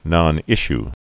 (nŏnĭsh)